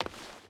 Footsteps / Stone / Stone Run 5.wav
Stone Run 5.wav